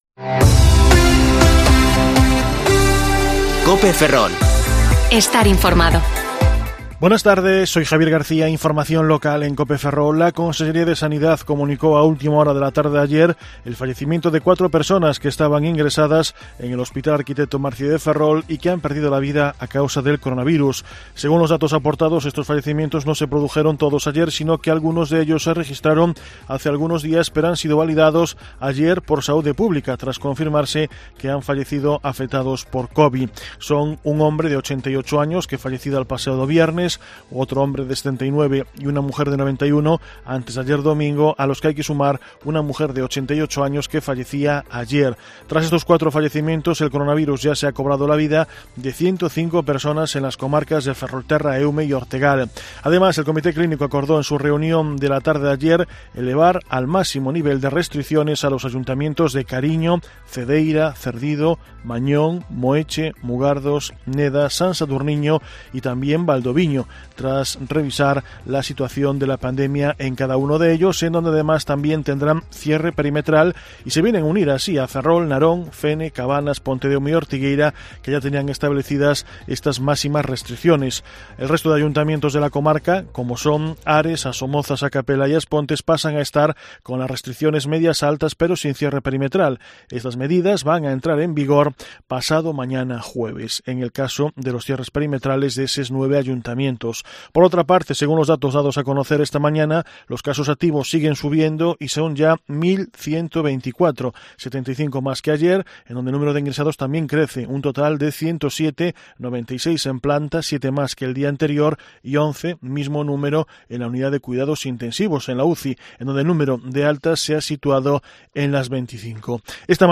Informativo Mediodía COPE Ferrol 19/1/2021 (De 14,20 a 14,30 horas)